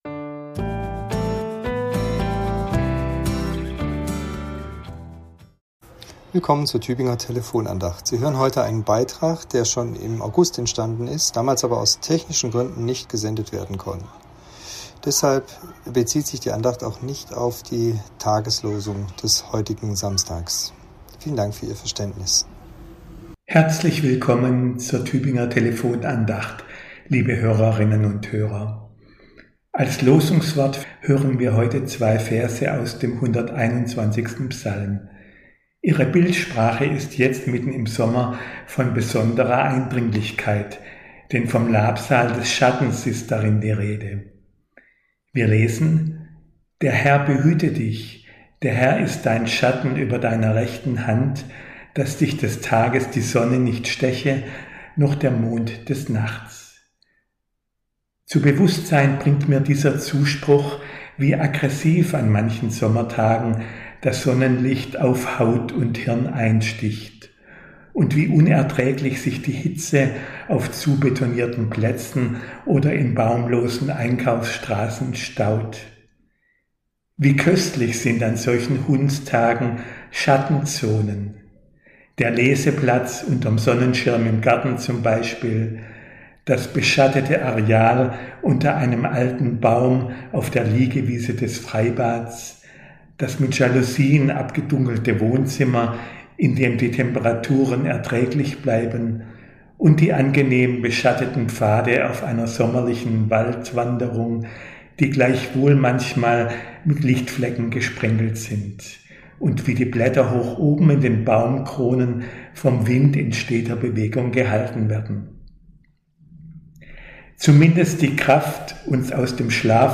Andacht zu einer Losung im August